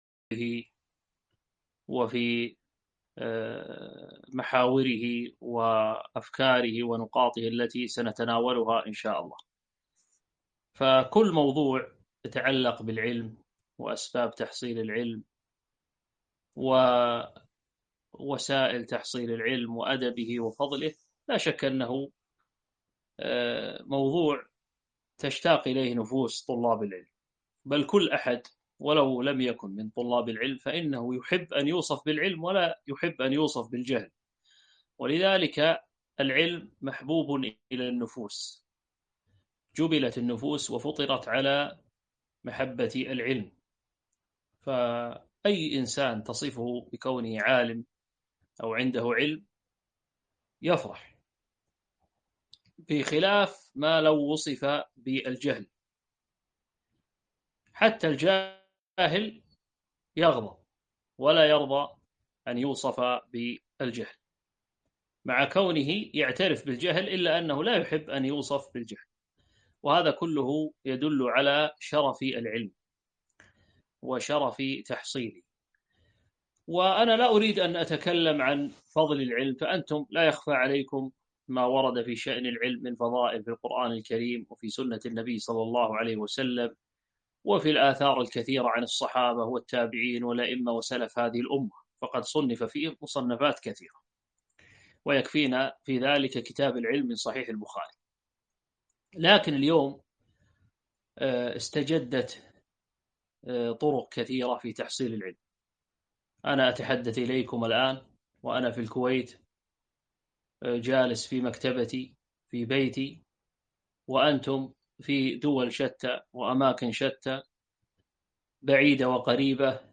محاضرة نافعة: أسس حضور الدرس وتقييد الفوائد